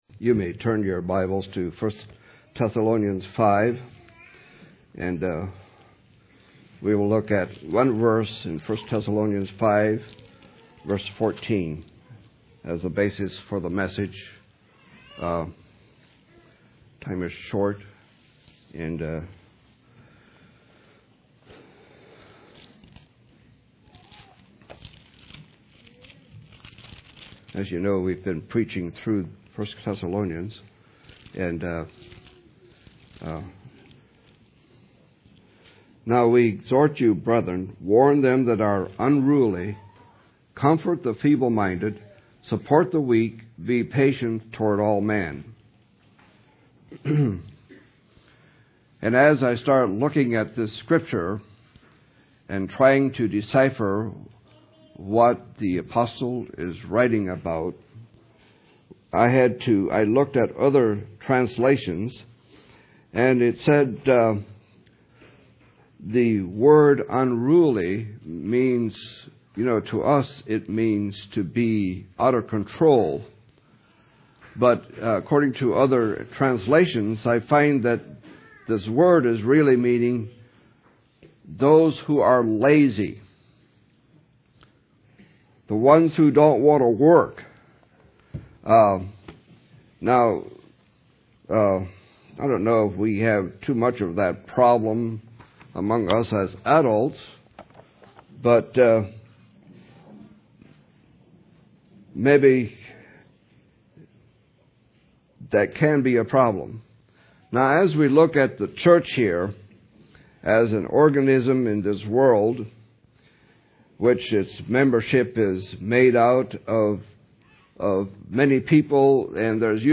August 31, 2014 – Crosspointe Mennonite Church